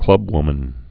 (klŭbwmən)